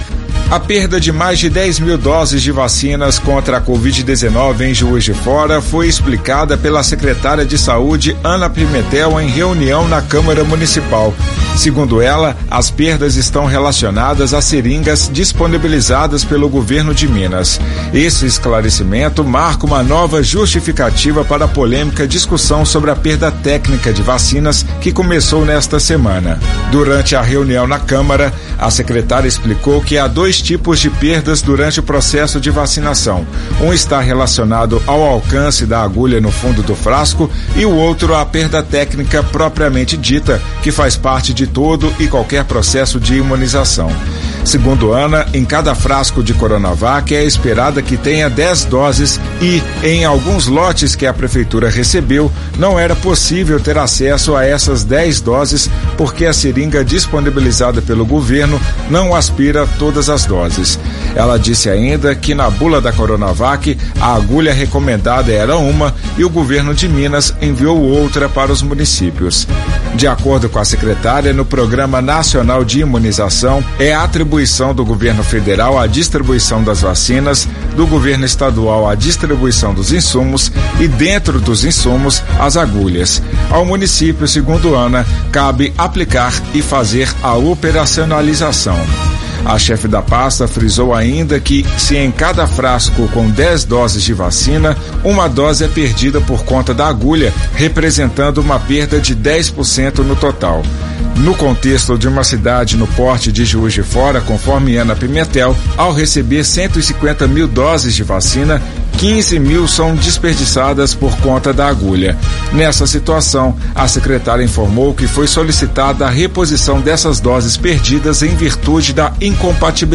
A perda de mais de 10 mil doses de vacinas contra a covid-19, em Juiz de Fora, foram explicadas pela secretária de Saúde, Ana Pimentel, em reunião na Câmara Municipal, nesta sexta-feira, 28.